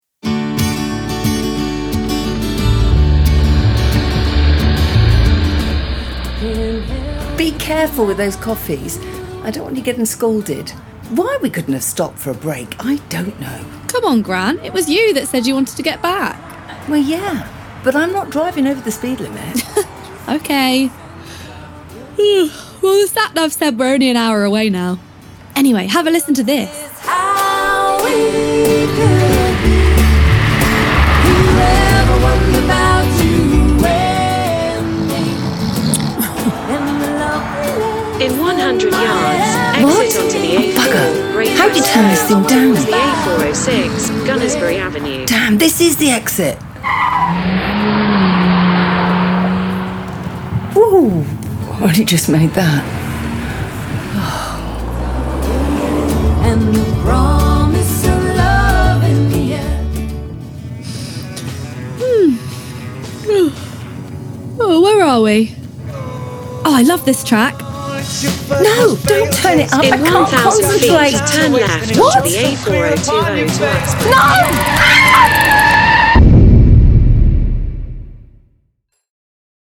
PLAYING WOODSTOCK – Audio Play
This was originally recorded as a table read, in a room with less-than-ideal acoustics. A narrator read the scenes and action lines. The audio was all comped from 2 takes, edited, and all narration, taken out. Some additional dialogue was then recorded remotely and matched in. Music, Foley and SFX were then added to complete a very ‘visual’ audio play.
Playing-Woodstock-Audio-Play-Carrie-and-Ellen-Car-Crash-Scene.mp3